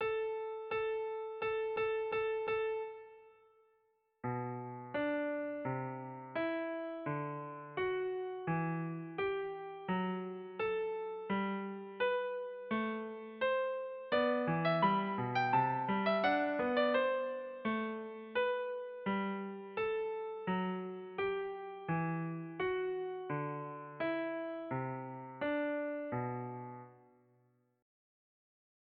Kleines Übungsstück 3 in G-Dur für Violine
Digitalpiano Casio CDP-130